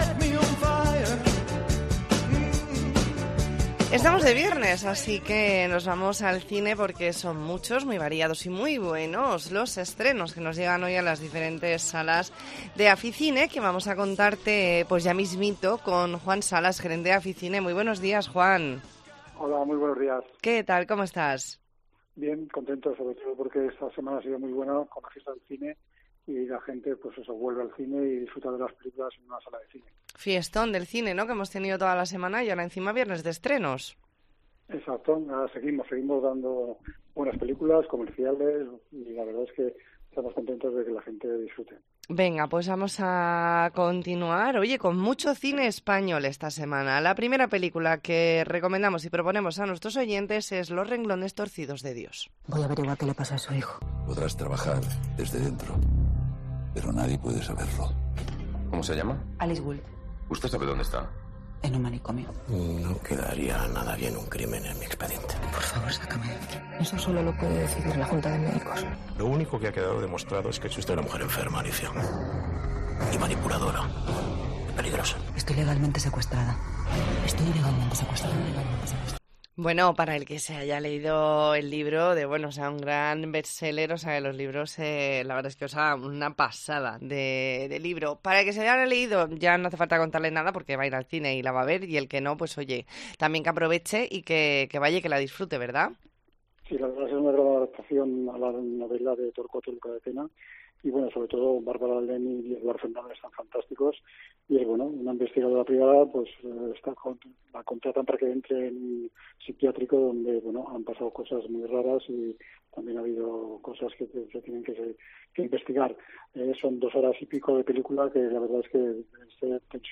. Entrevista en La Mañana en COPE Más Mallorca.